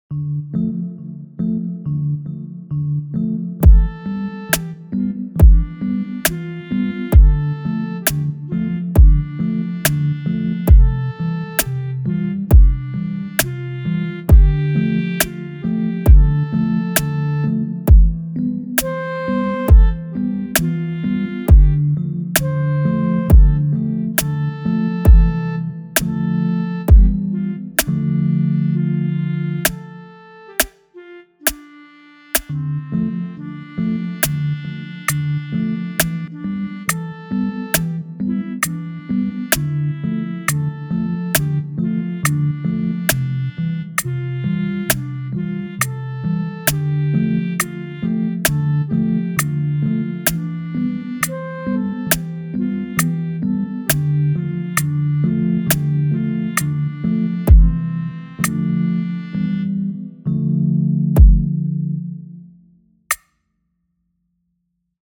– ohne Worte –